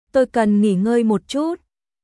Tôi cần nghỉ ngơi một chút.少し休養が必要ですトイ カン ンギー ンゴイ モッ チュット